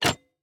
Minecraft Version Minecraft Version snapshot Latest Release | Latest Snapshot snapshot / assets / minecraft / sounds / block / copper_trapdoor / toggle3.ogg Compare With Compare With Latest Release | Latest Snapshot